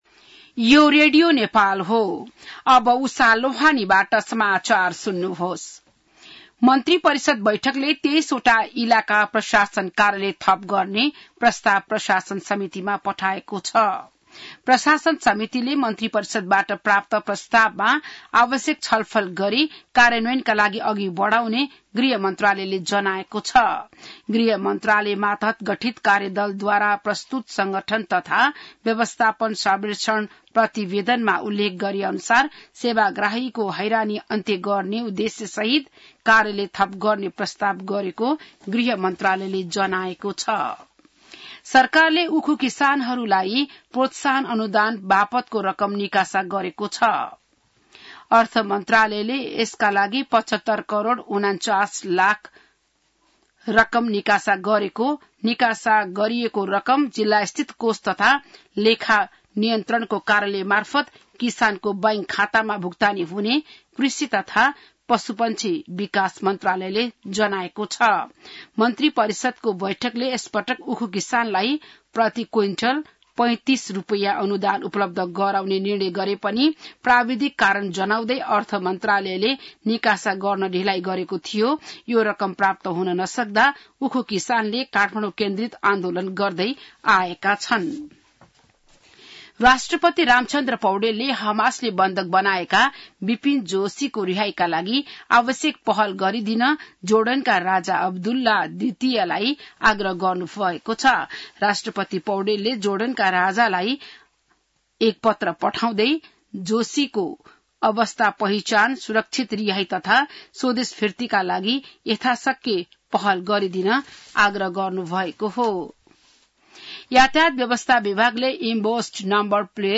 बिहान १० बजेको नेपाली समाचार : ११ भदौ , २०८२